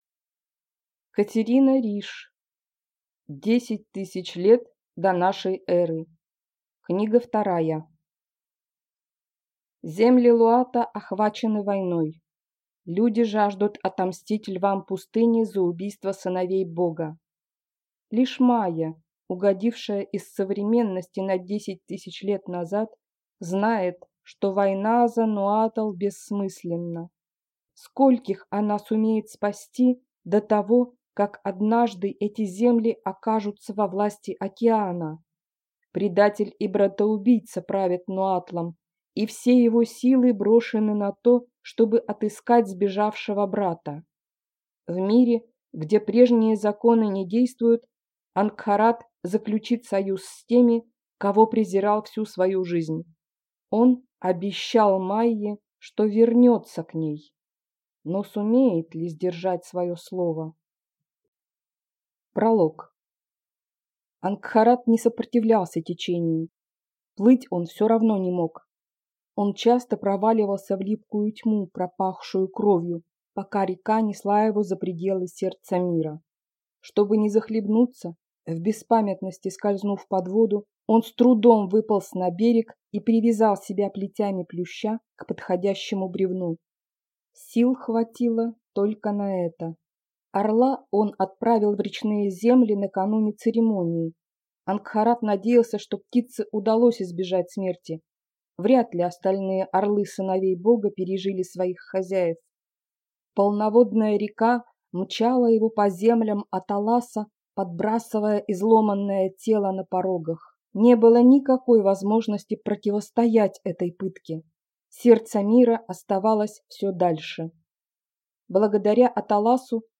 Аудиокнига 10000 лет до нашей эры. Книга 2 | Библиотека аудиокниг
Прослушать и бесплатно скачать фрагмент аудиокниги